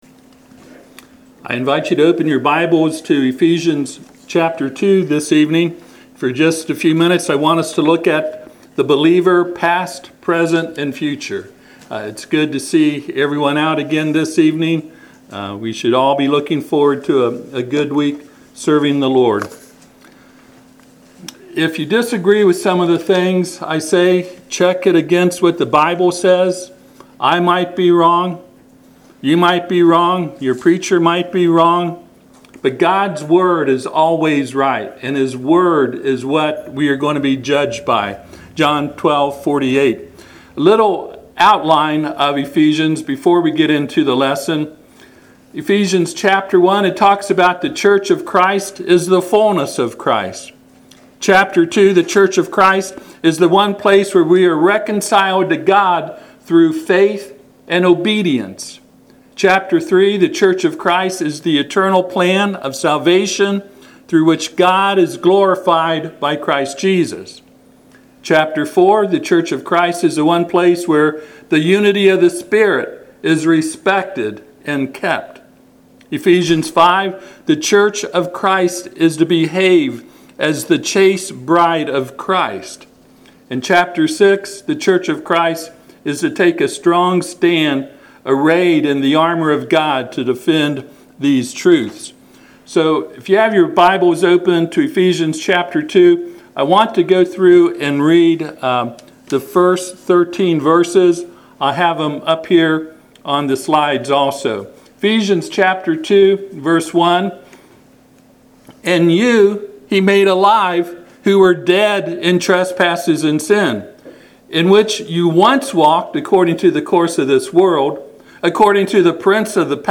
Passage: Ephesians 2:1-5 Service Type: Sunday PM